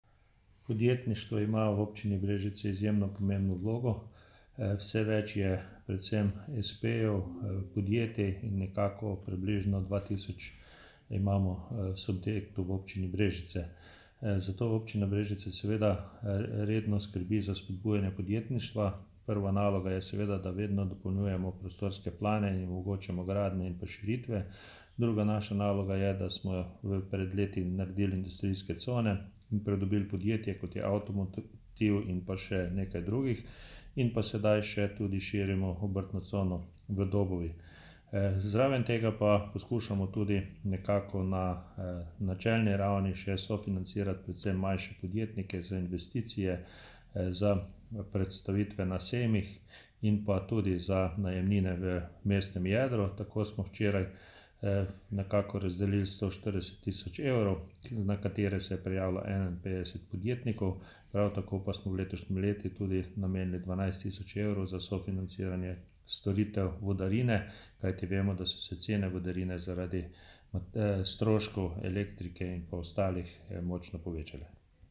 Župan Ivan Molan o Javnem razpisu za spodbude podjetništvu 2023